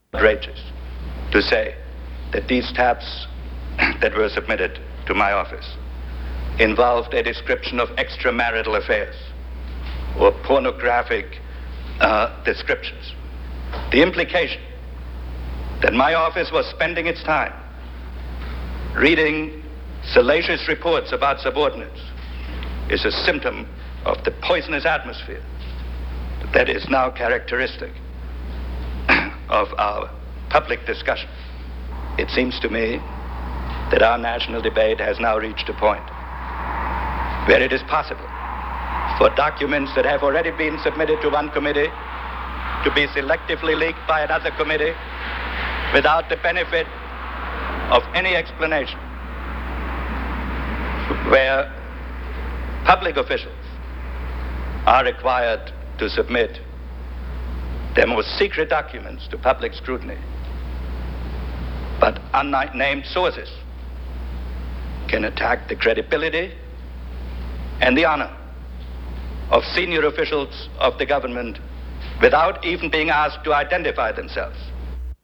Henry Kissinger emotionally denies ordering wiretapping of his associates